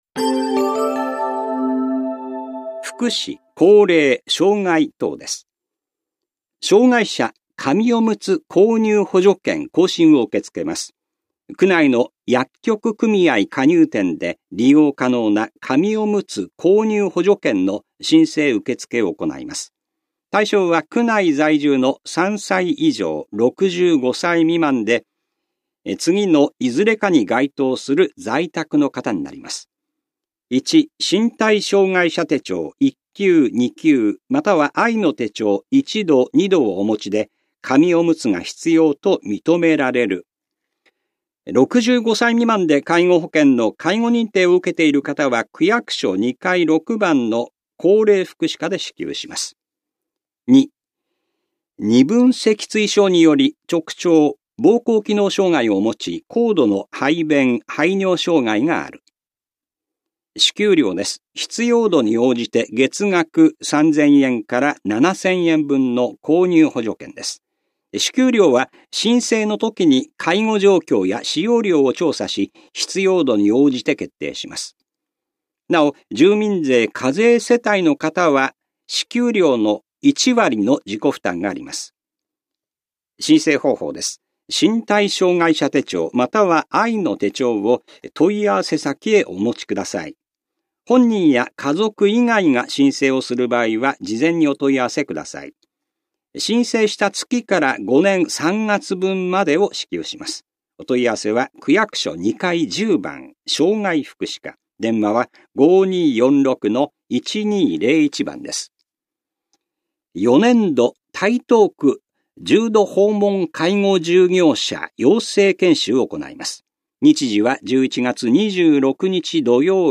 広報「たいとう」令和4年10月5日号の音声読み上げデータです。